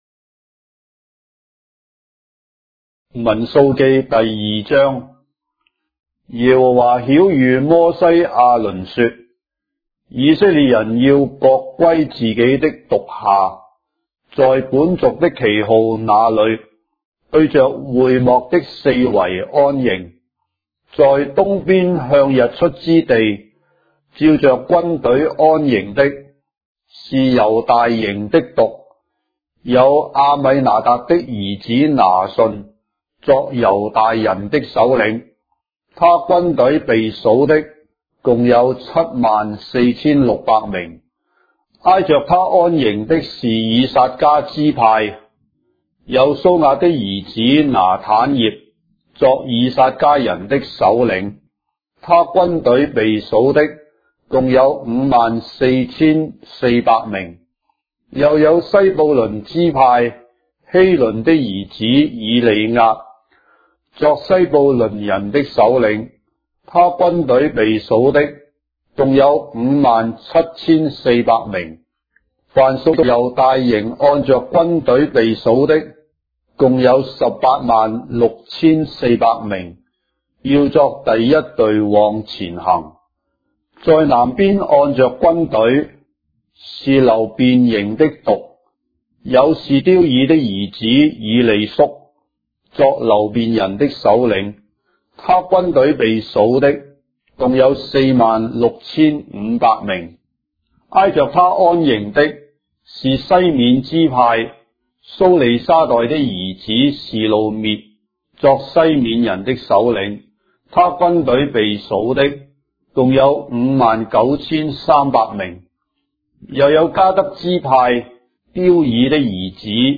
章的聖經在中國的語言，音頻旁白- Numbers, chapter 2 of the Holy Bible in Traditional Chinese